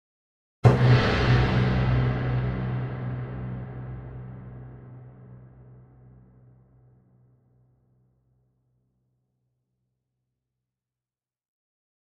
Gong Symphonic Gong Hit